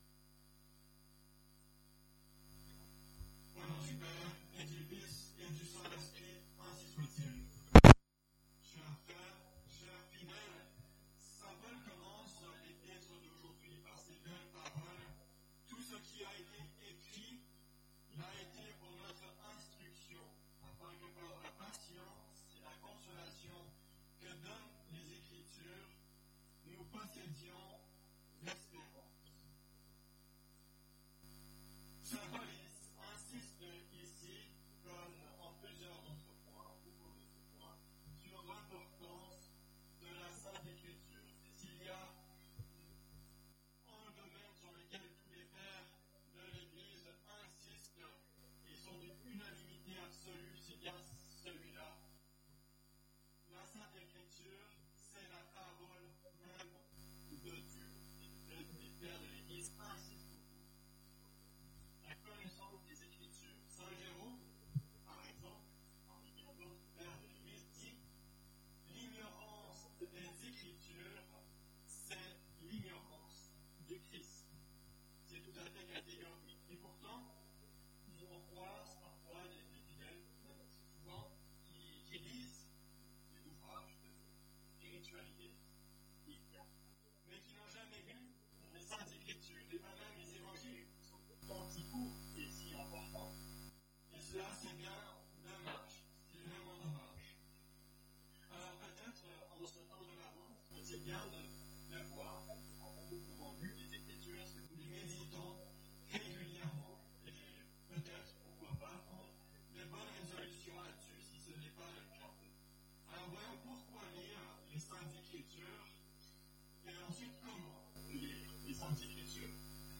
Occasion: Deuxième dimanche de l’Avent
Type: Sermons